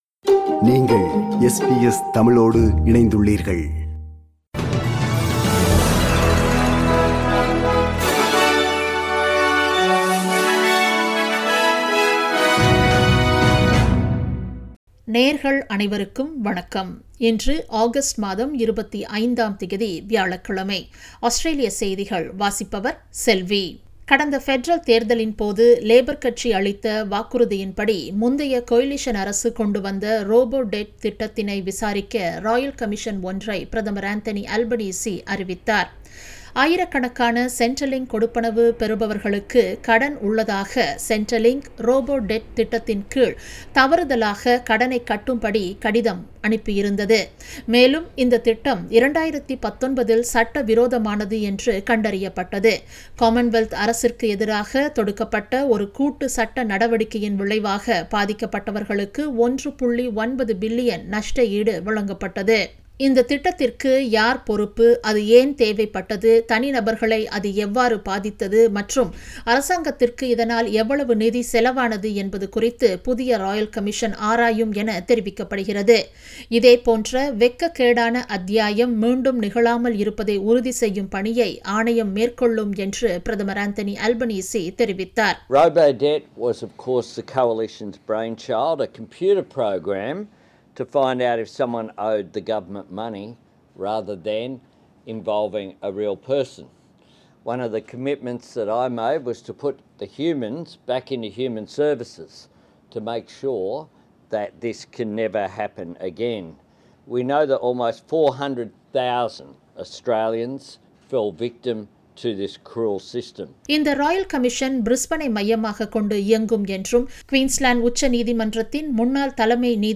Australian news bulletin for Thursday 25 Aug 2022.